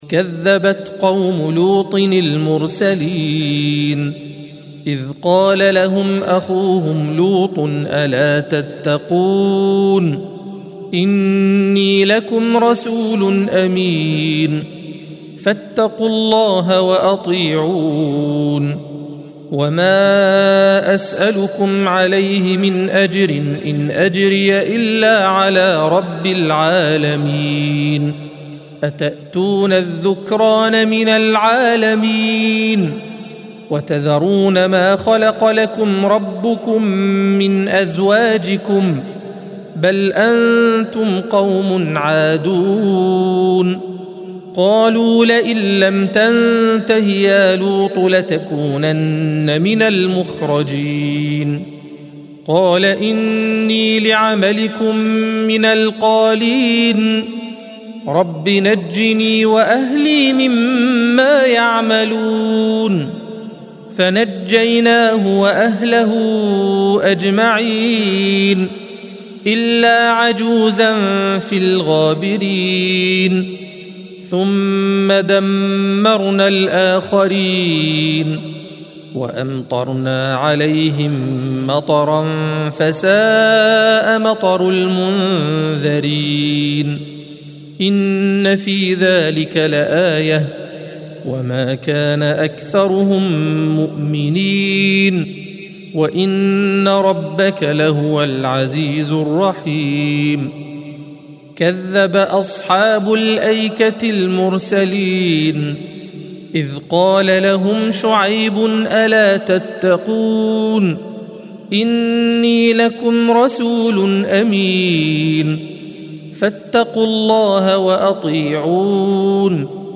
الصفحة 374 - القارئ